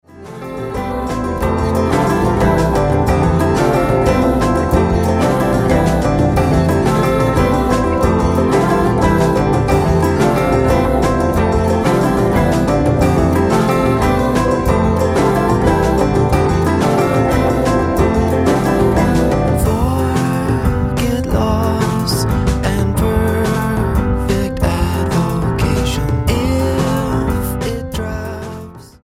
STYLE: Roots/Acoustic
Piano, banjo and trumpet